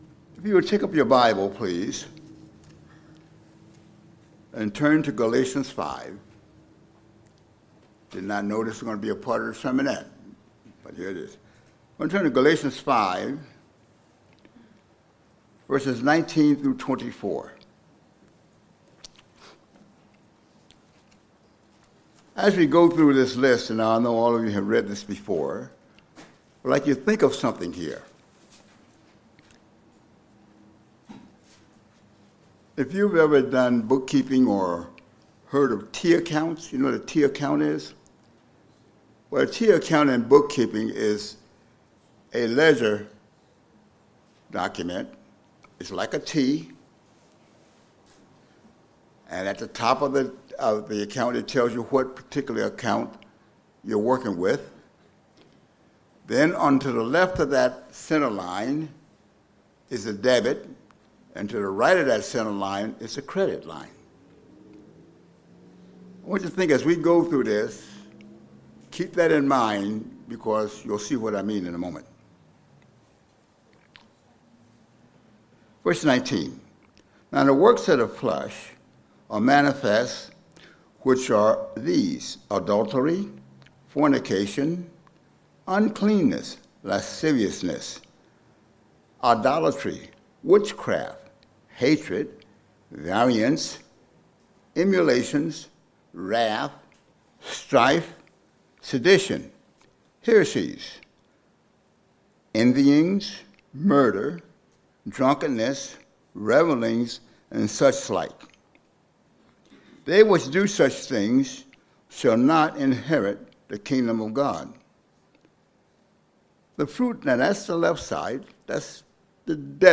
Sermons
Given in Delmarva, DE